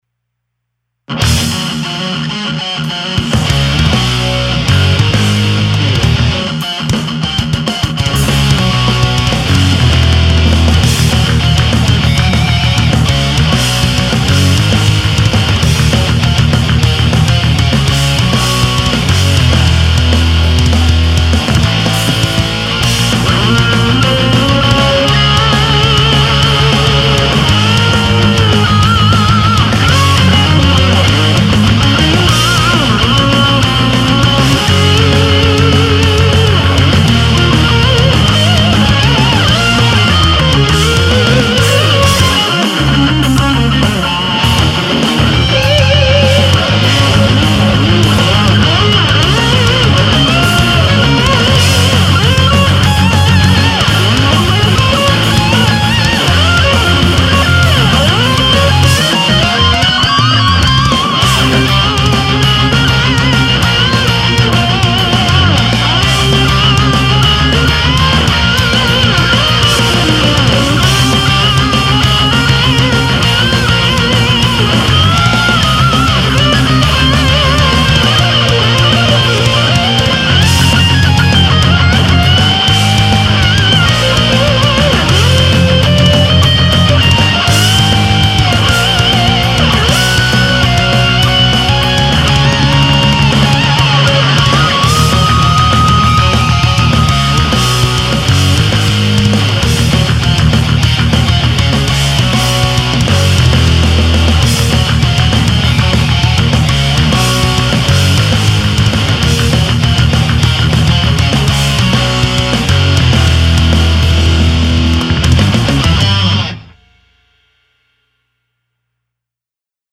ハード・ロック系のフリーBGM
メロディアスなハードロック曲